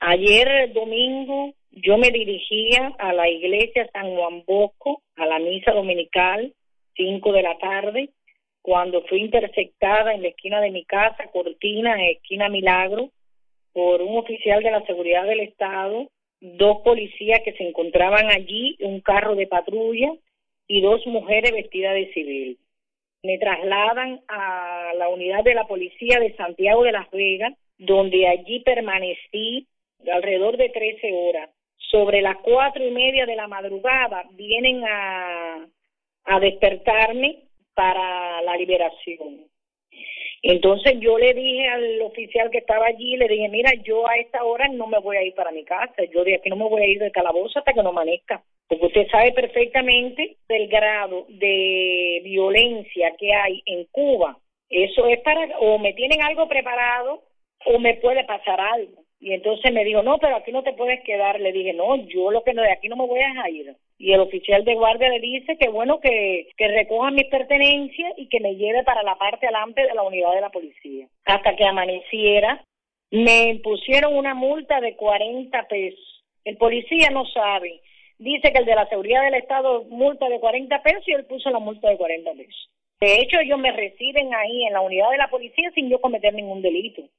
Dama de Blanco